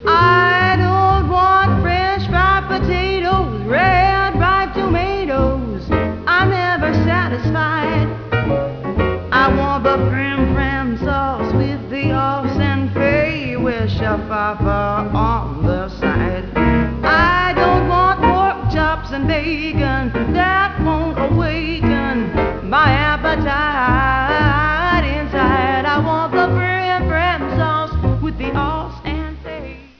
And, fundamentally -- above all -- she swings.